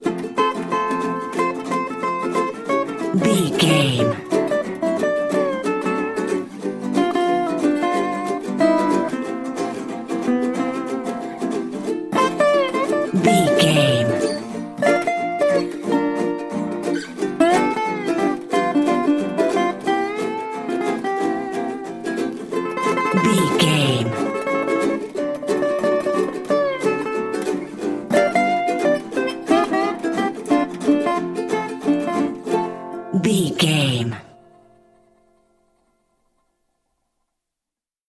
Ionian/Major
acoustic guitar
ukulele
slack key guitar